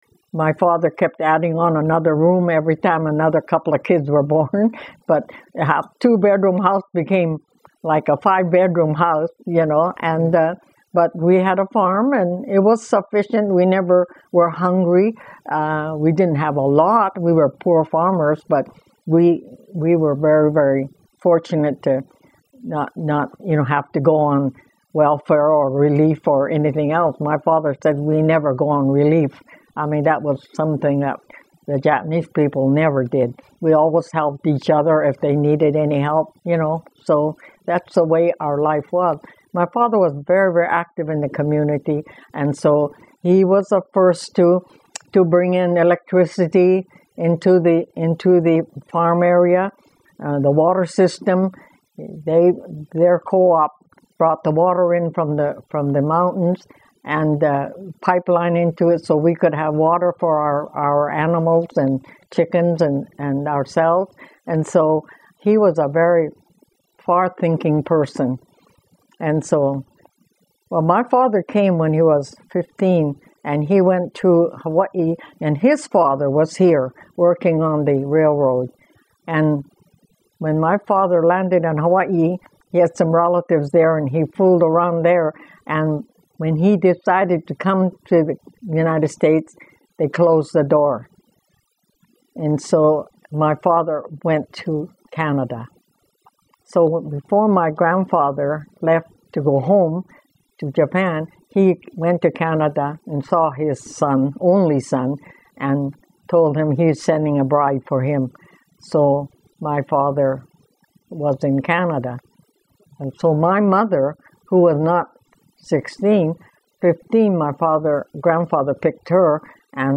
This was one of the longest interviews I've conducted so far.